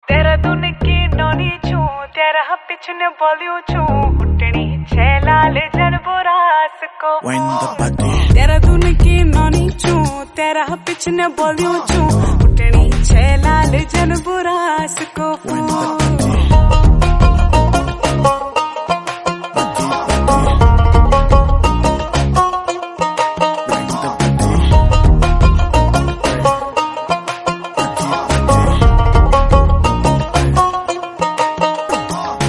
garhwai song